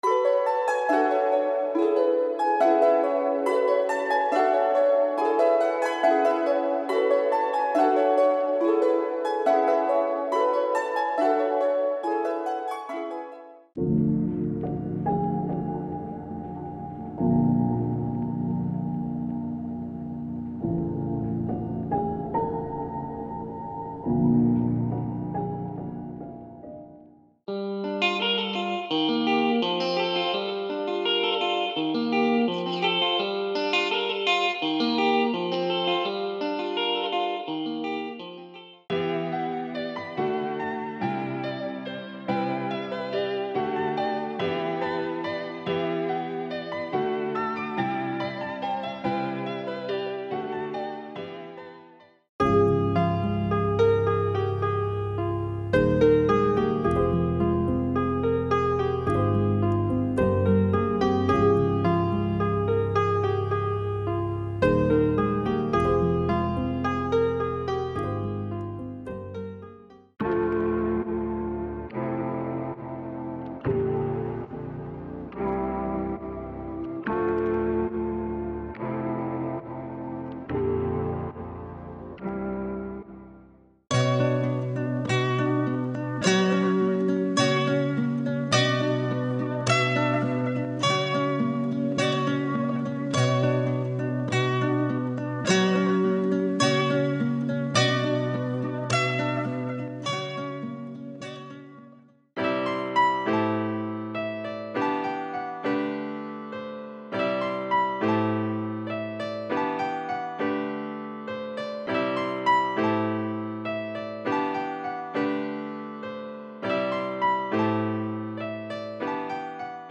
所有音频文件均经过专业混音和母带处理。